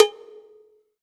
PCOWBELL.wav